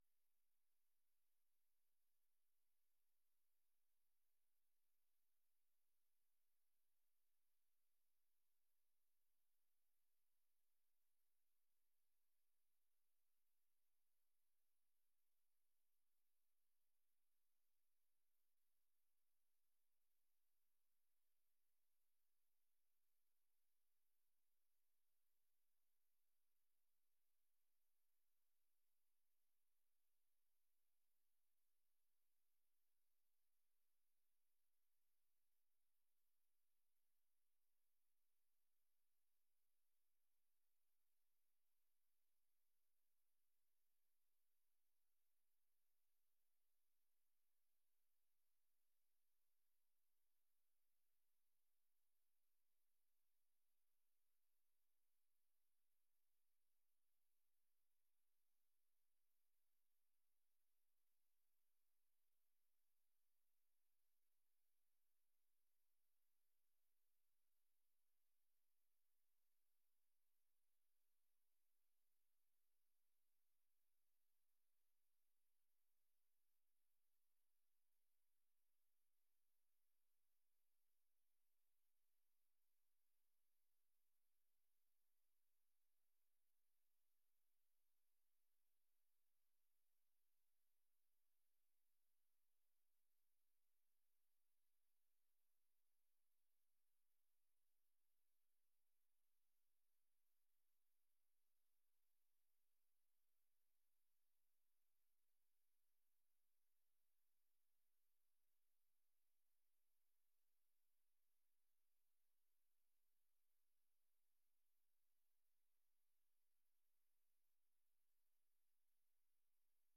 Ook worden deze vergaderingen live uitgezonden via onze website en blijven beschikbaar, in het openbaar archief van de website, om terug te kijken.